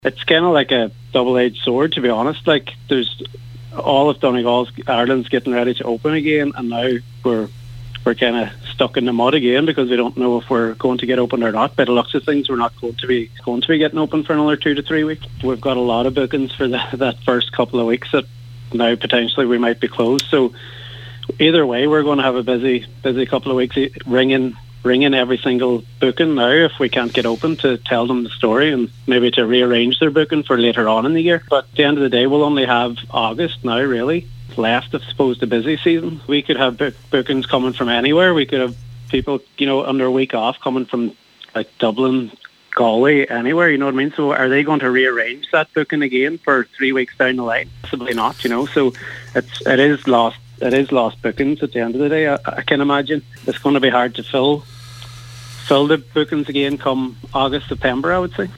He told today’s Nine til Noon Show that pushing back the reopening date for indoor dining will be a huge hit to revenue: